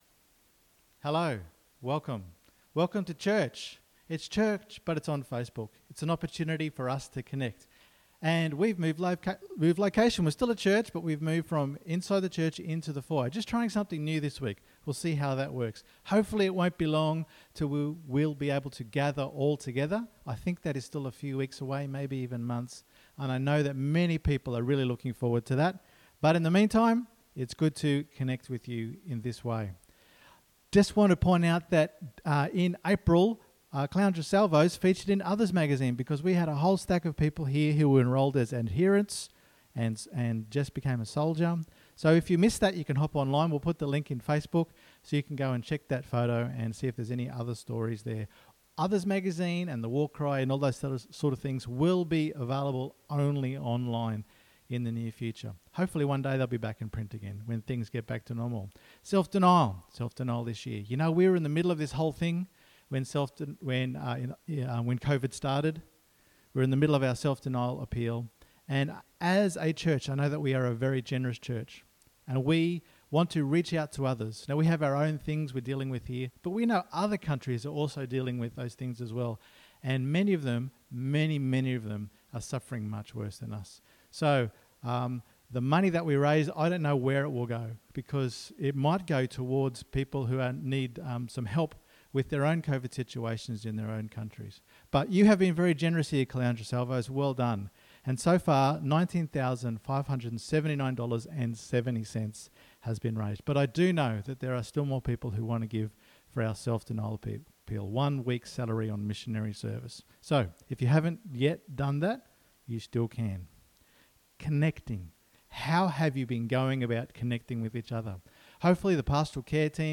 Sunday_Meeting_3rd_May_2020_Audio.mp3